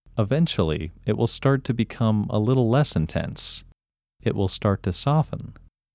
IN – First Way – English Male 12
IN-1-English-Male-12.mp3